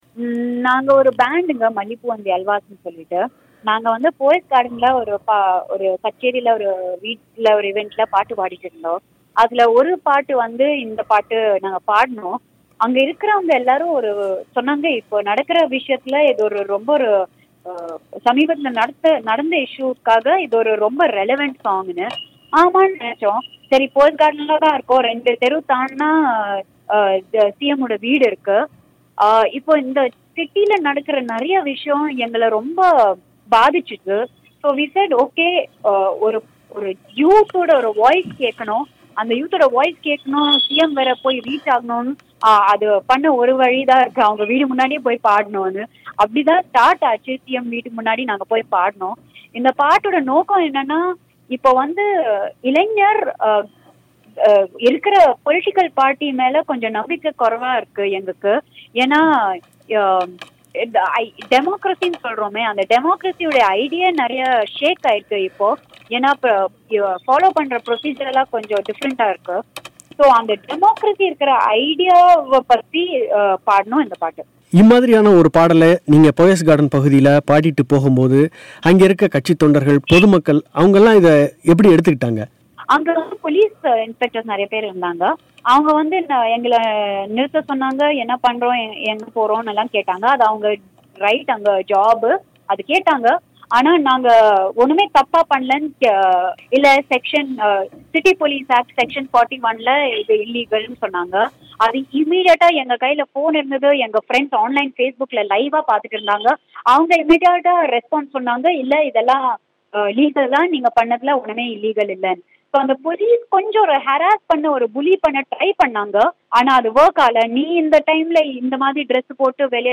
பிபிசிக்கு அளித்த பேட்டி